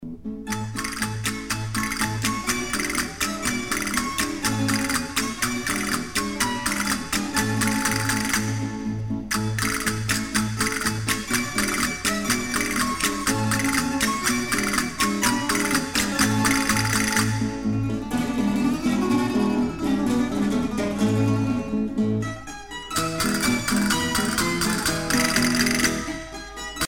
danse : paso-doble